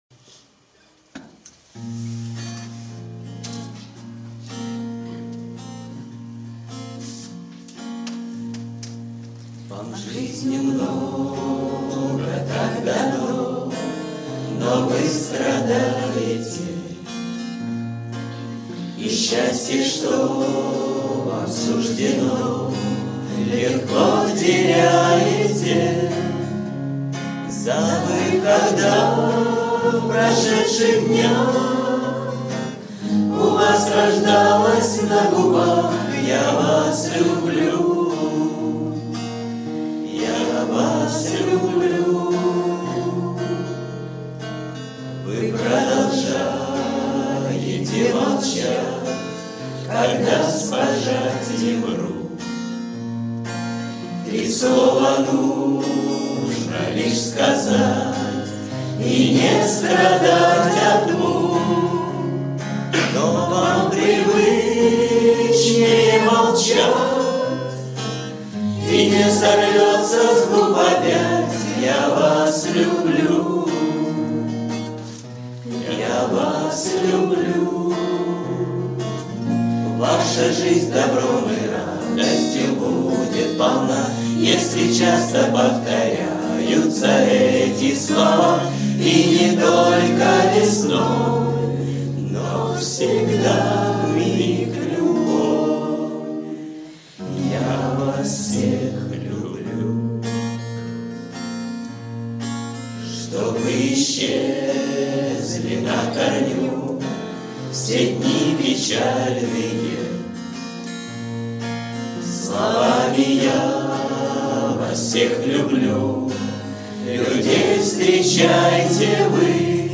кавер-версия
Песни у костра